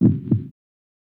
Roland.Juno.D _ Limited Edition _ GM2 SFX Kit _ 07.wav